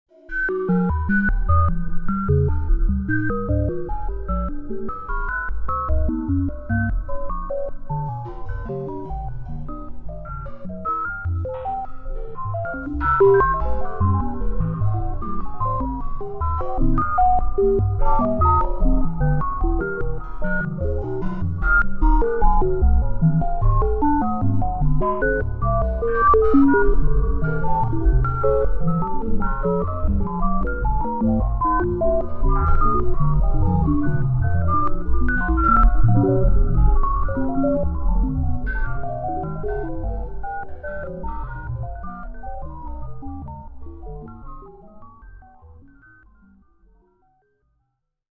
Creepy Space Sci-Fi Ambience Sound Effect
Description: Creepy space sci-fi ambience sound effect. Experience an eerie, mysterious atmosphere with unpleasant, confusing computerized noise.
Creepy-space-sci-fi-ambience-sound-effect.mp3